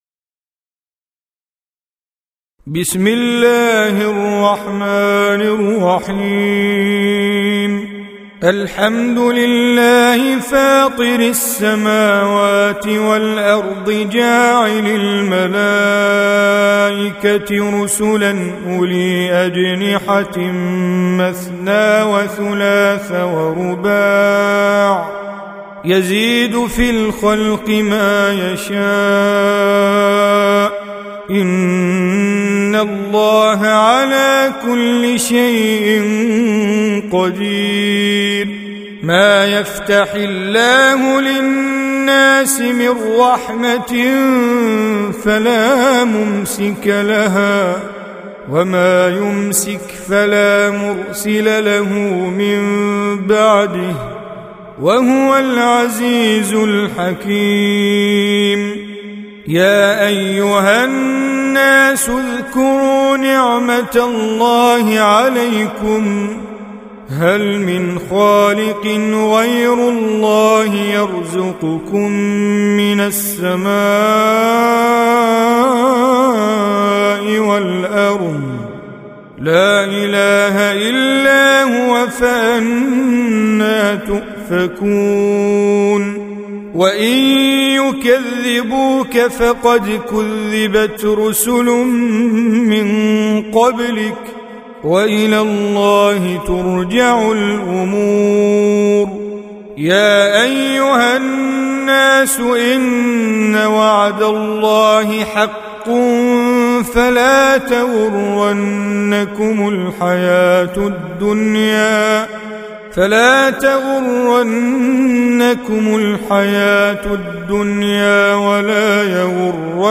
35. Surah F�tir or Al�Mal�'ikah سورة فاطر Audio Quran Tajweed Recitation
Surah Repeating تكرار السورة Download Surah حمّل السورة Reciting Mujawwadah Audio for 35.